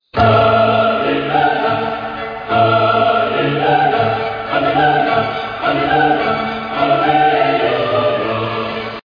Christian & Gospel RingTones